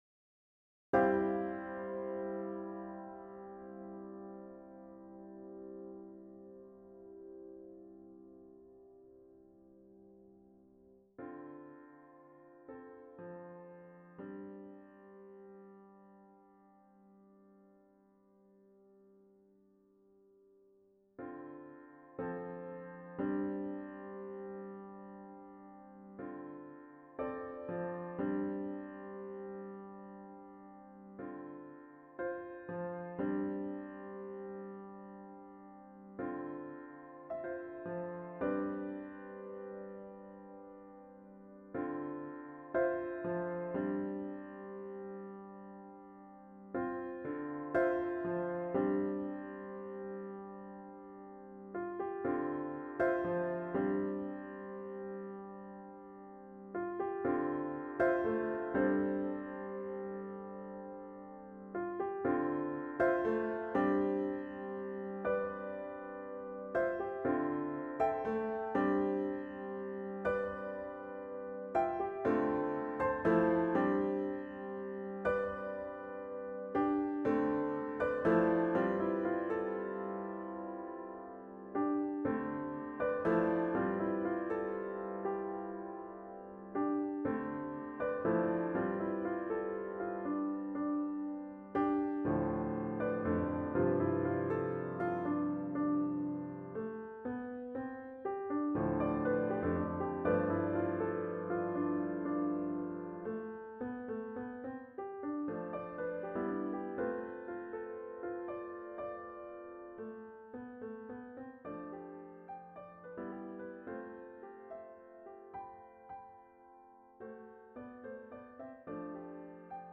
Composed in 2016 - Minimalis 1 was initially a structural experiment, whereby minimalist techniques of repetition and micro-variation are applied to single-movement structures of robust and rigorously proportioned quasi-symphonic architecture.